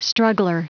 Prononciation du mot struggler en anglais (fichier audio)
Prononciation du mot : struggler